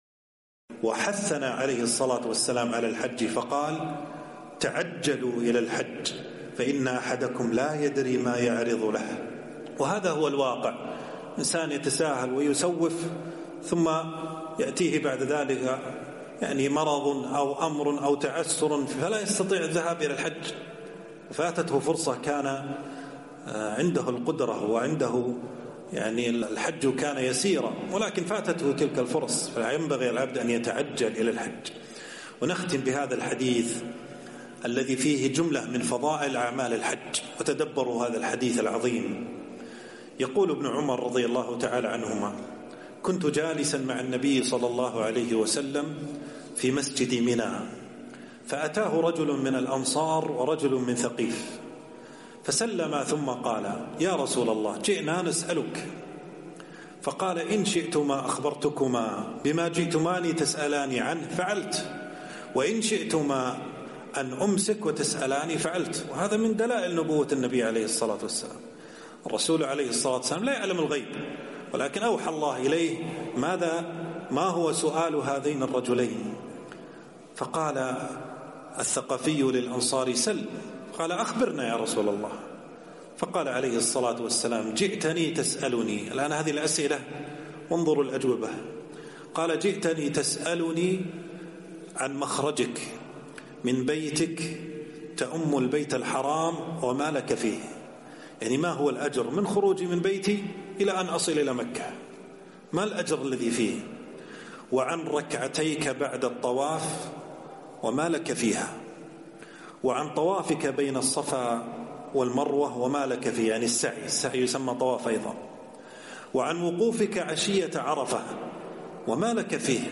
موعظة الحث على المبادرة إلى الحج